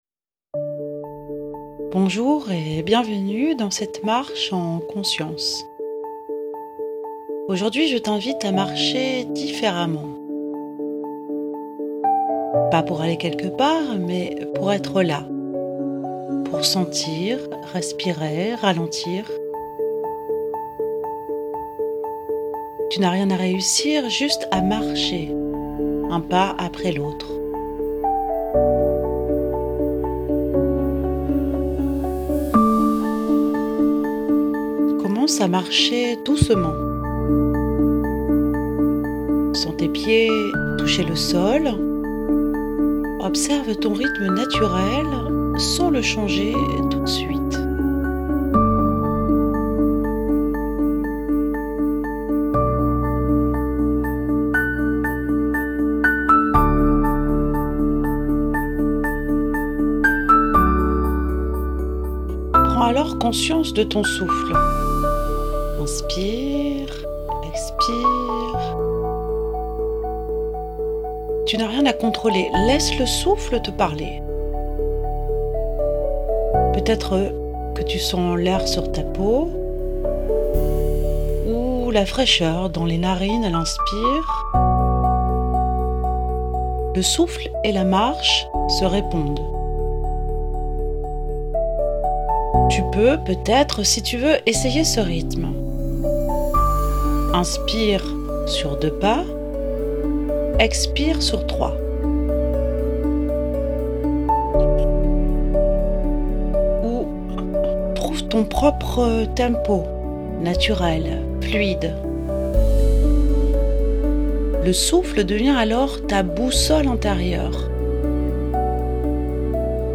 ✨ Esta meditación guiada te ayudará a :